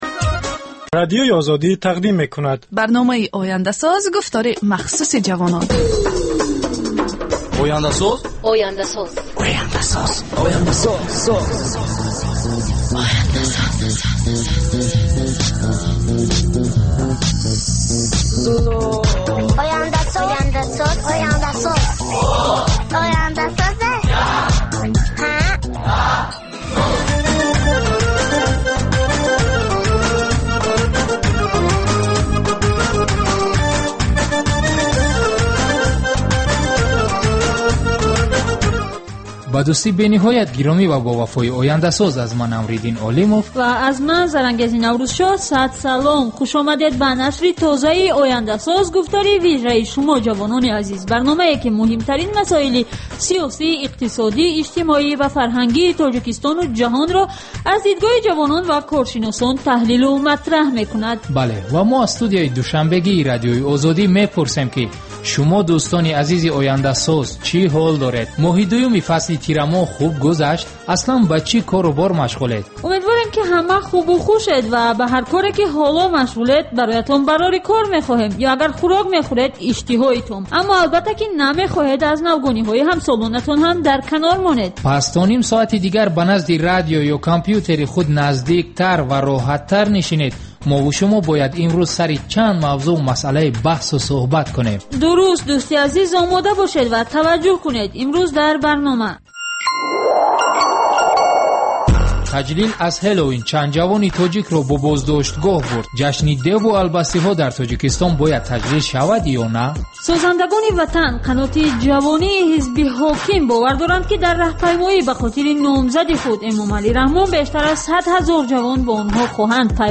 Новости стран Центральной Азии.